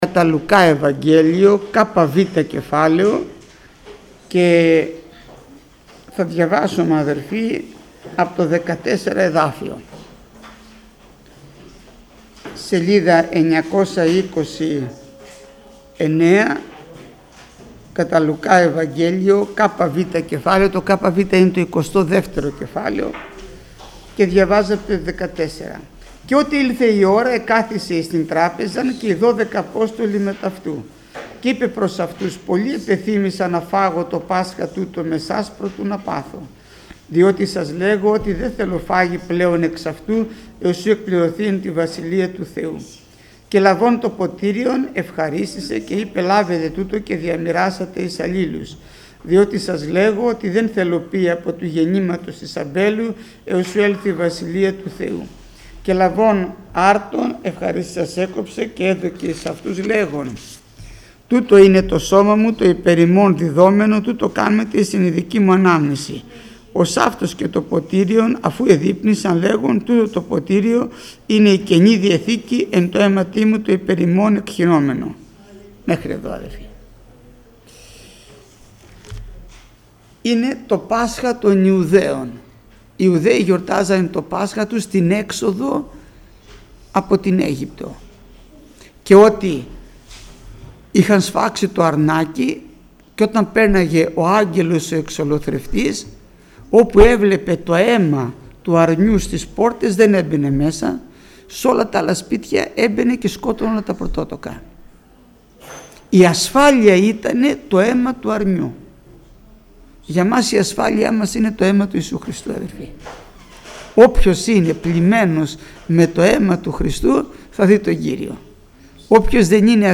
Μήνυμα πριν τη θεία κοινωνία